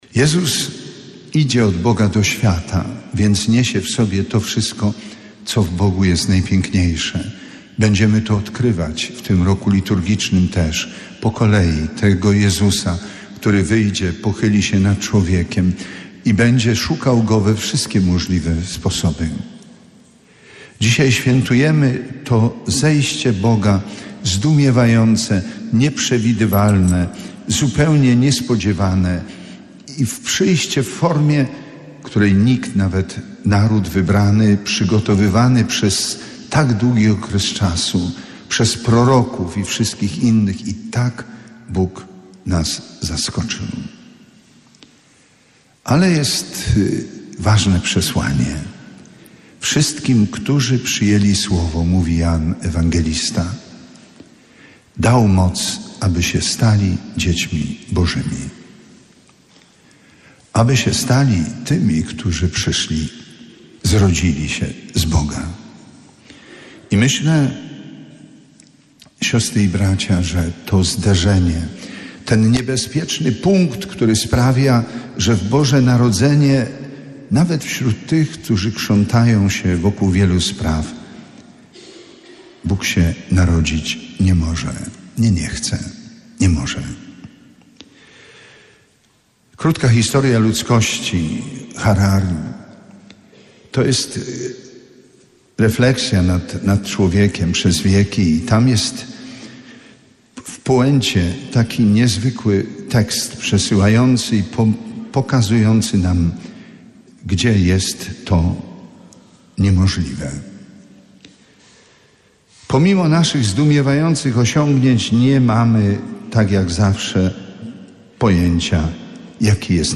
W koszalińskiej katedrze Pasterce o północy przewodniczył bp Edward Dajczak.
24.12 Pasterka bp Dajczak homilia 2.mp3